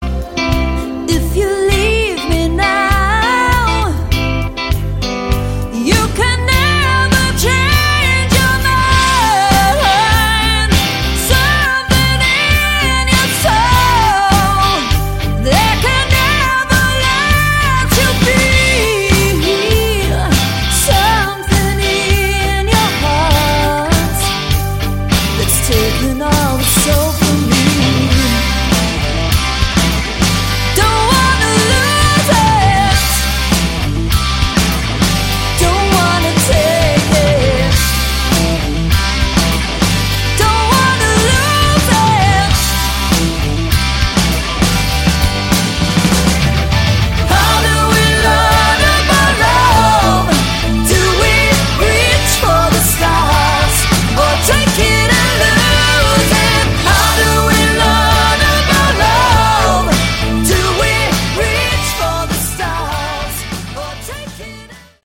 Category: Hard Rock
lead vocals
lead guitar, backing vocals
bass Guitar
keyboards